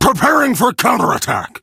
ruff_kill_vo_02.ogg